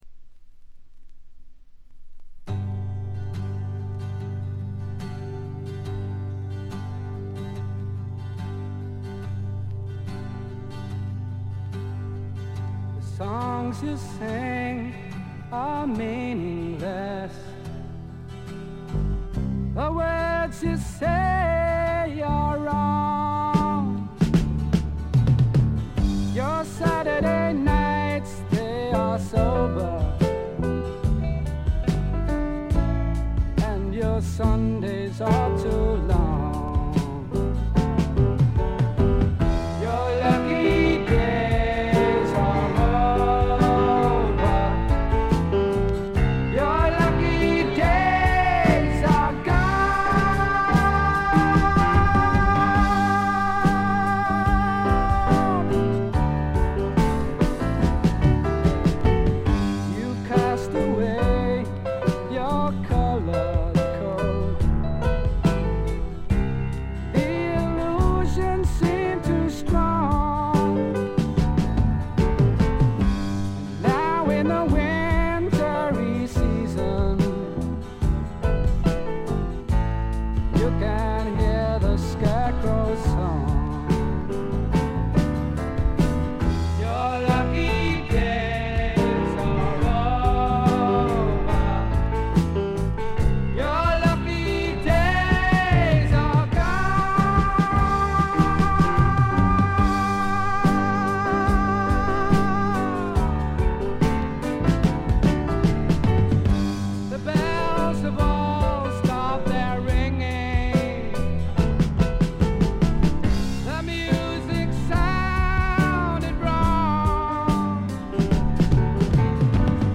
わずかなノイズ感のみ。
英国フォークロック基本！
試聴曲は現品からの取り込み音源です。